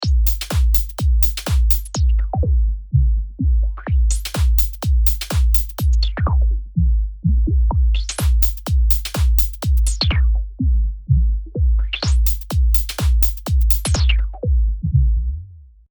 FREQを100％にしてみました。こうすることでポインターが左側にあるとフィルターが閉まり、右に向かうにつれて開いていきます。